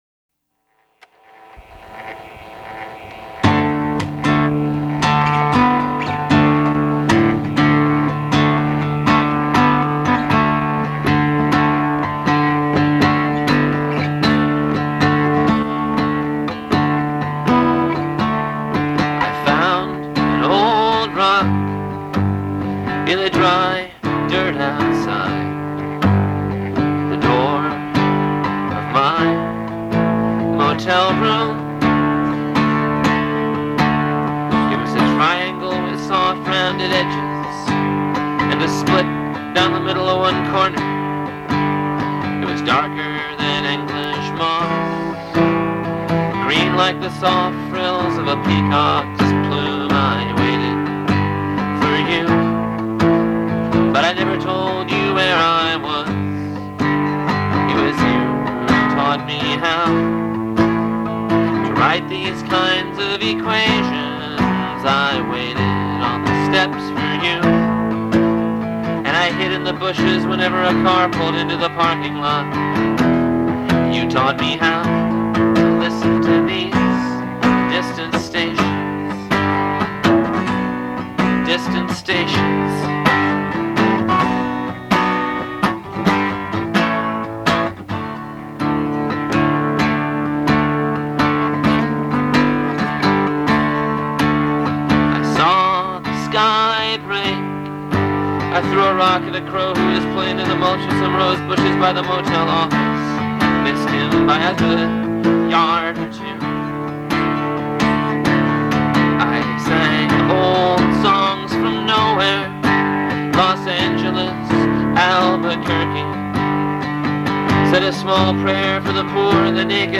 Dark Folk.